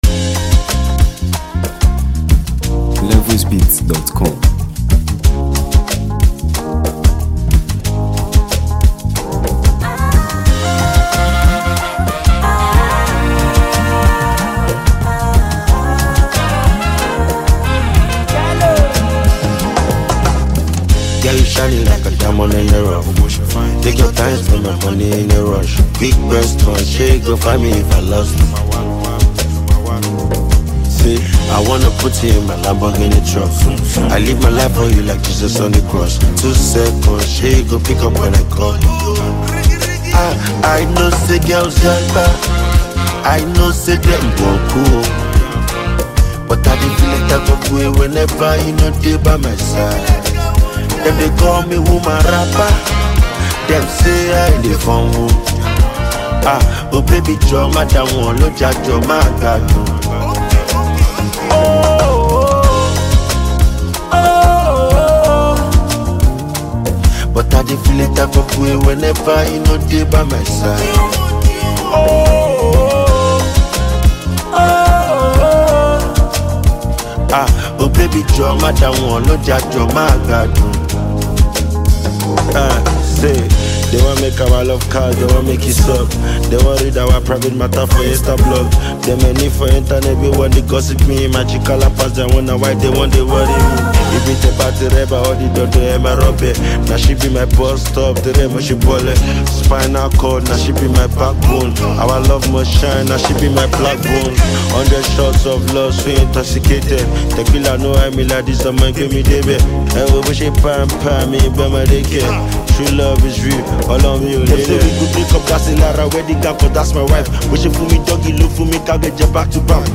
infectious rhythm
signature street-inspired delivery